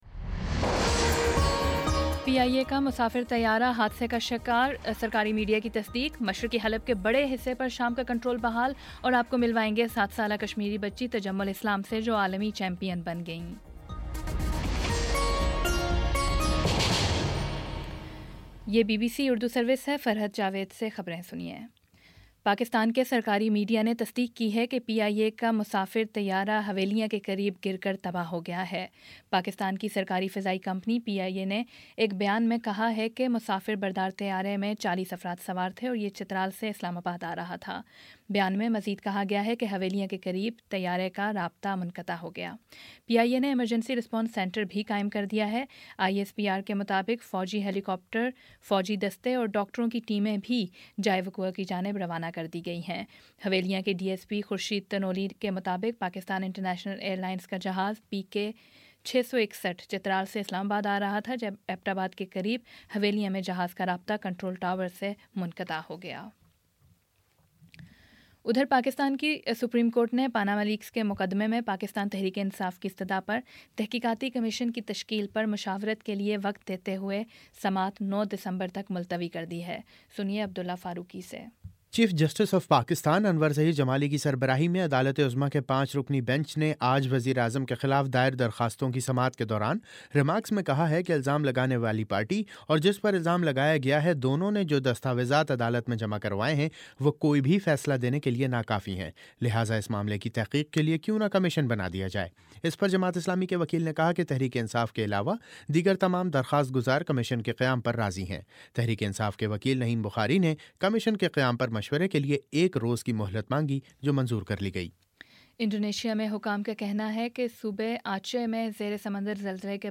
دسمبر 07 : شام چھ بجے کا نیوز بُلیٹن